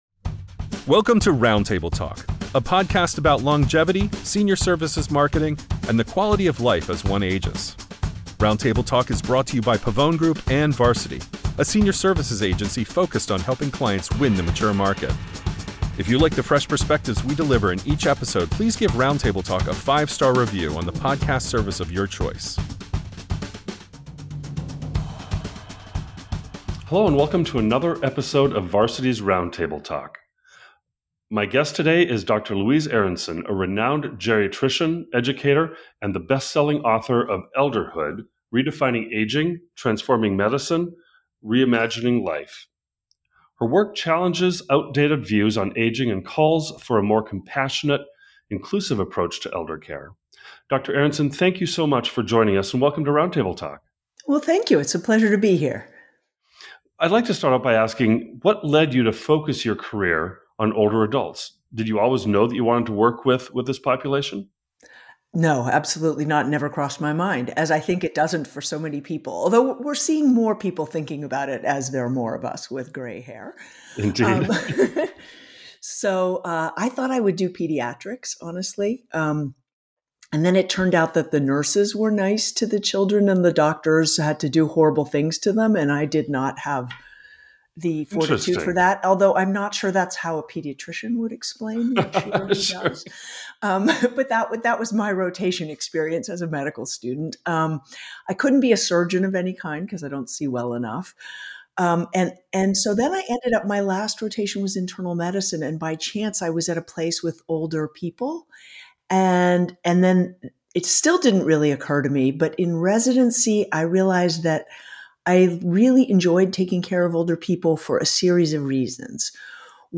On this episode of Varsity’s Roundtable Talk, we sit down with Dr. Louise Aronson, a nationally recognized geriatrician, educator, and the bestselling author of Elderhood.